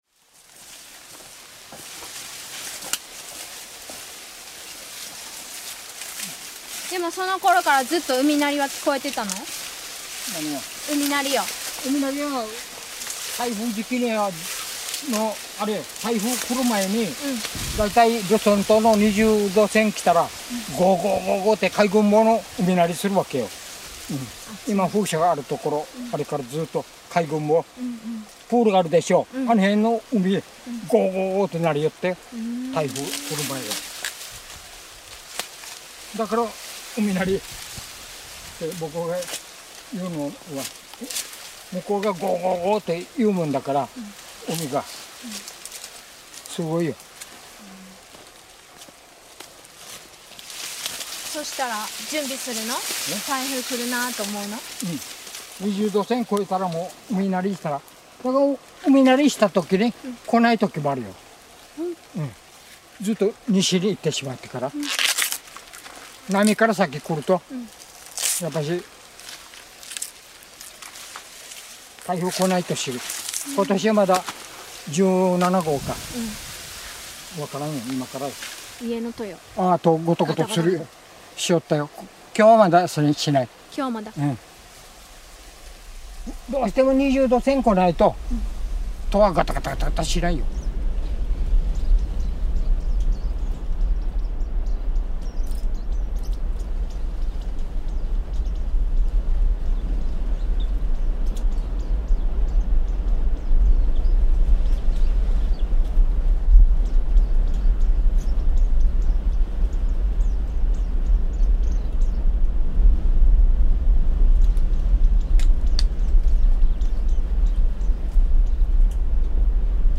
音源5-1 〈海鳴り〉『うみなりとなり　Soundscape of Minamidaito Island』（Otobin 2018）
音源5-1-海鳴り_01.mp3